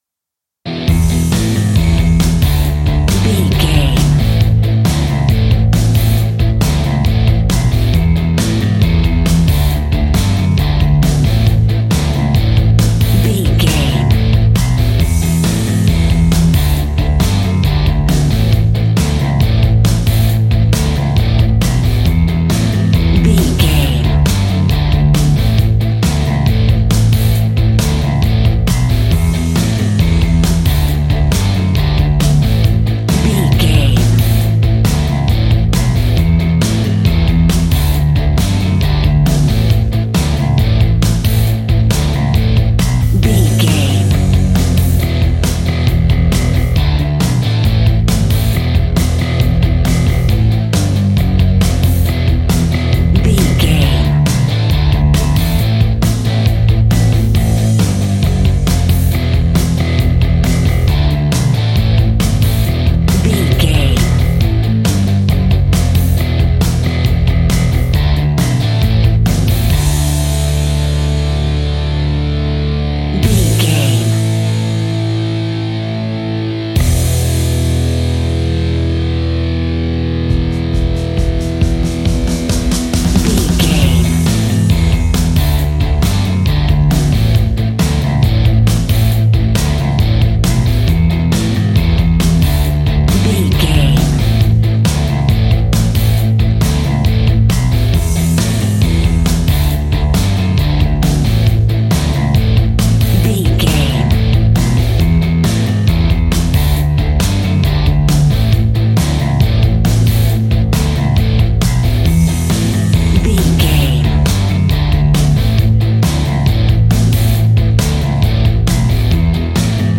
Fast paced
Ionian/Major
hard rock
distortion
punk metal
instrumentals
Rock Bass
Rock Drums
distorted guitars
hammond organ